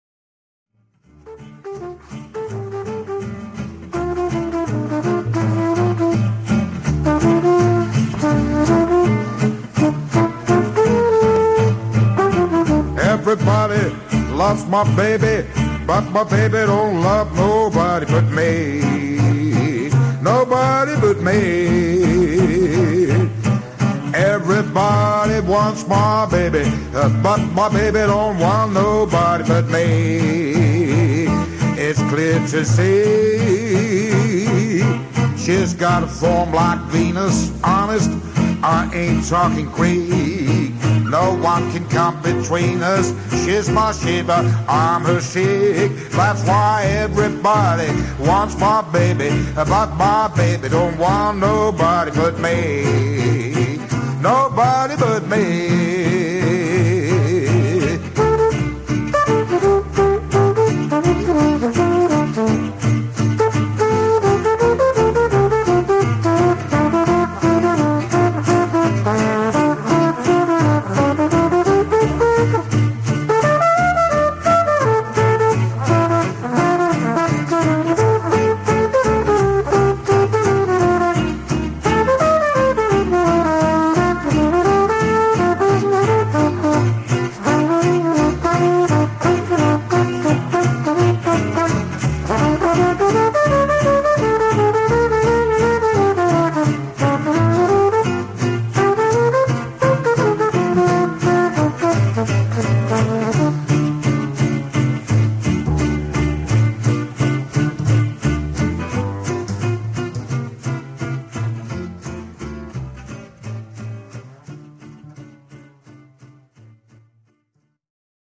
bass
guitar, vocal
cornet
guitar og sang
kontrabas